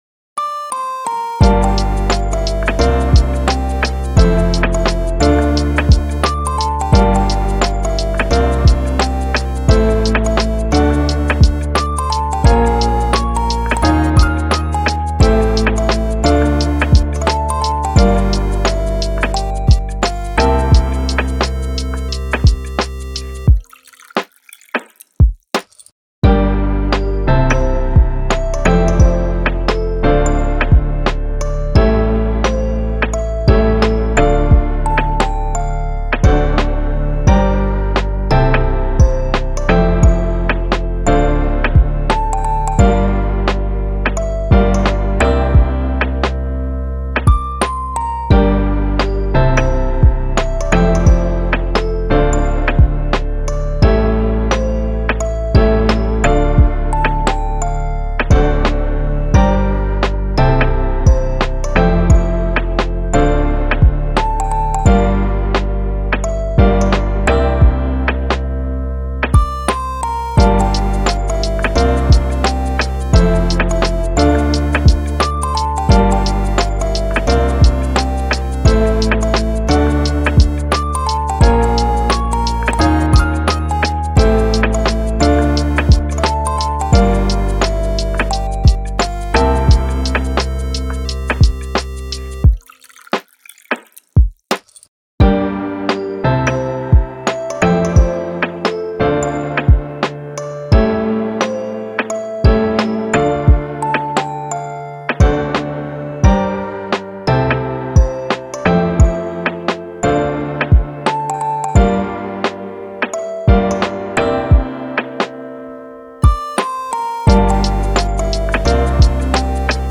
あかるい おしゃれ かわいい しっとり